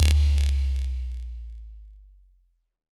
Bass Power Off 3.wav